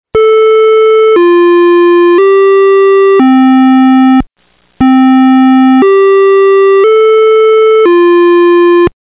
Westminster Chime
This tone cycles only one time per activation (approximately 10 seconds) Again this tone will be activated for non-emergency purposes only.
The Westminster Chime tone sounds like this:
Westminster-Chime-MP3